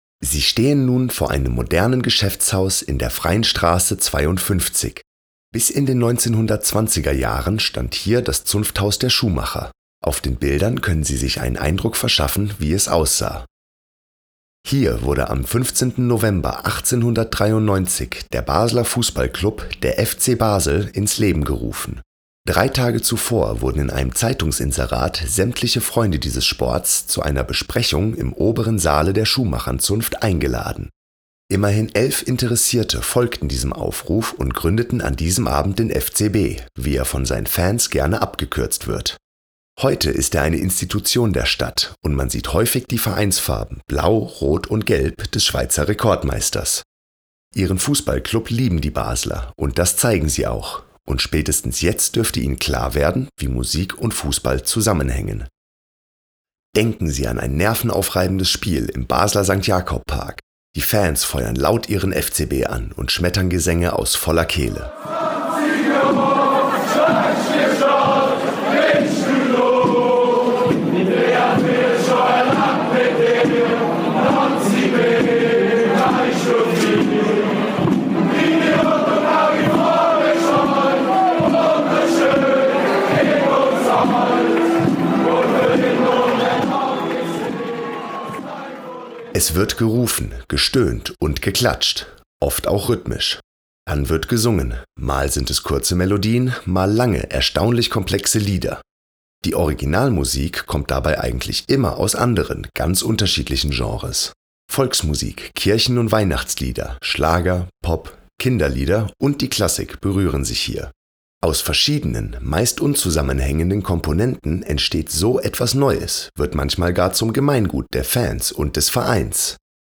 QUELLEN der Musikbeispiele:
Fangesang’:
Dean Martin, ‘Sway’ (1954):